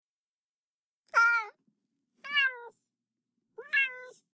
cat-meow.mp3